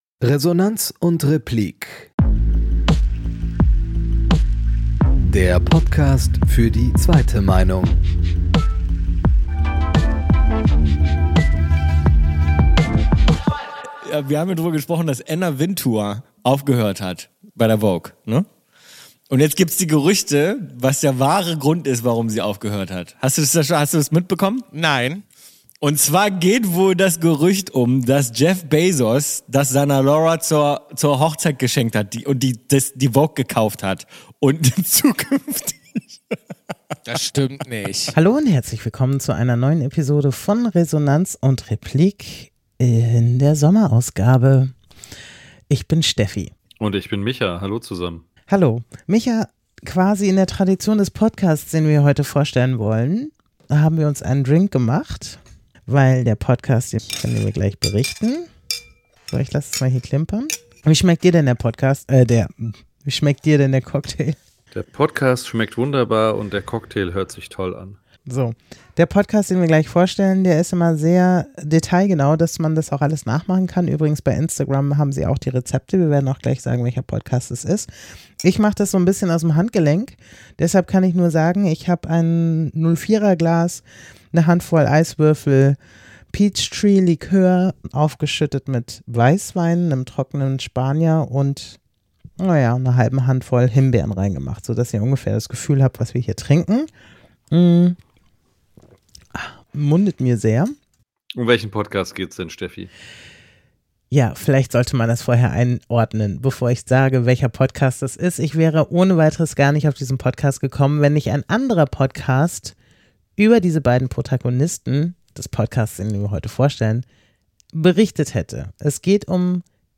Play Rate Listened List Bookmark Get this podcast via API From The Podcast Audio-Kolumne über Podcasts, die uns beschäftigen. Schwerpunkt Gesellschaft und Politik.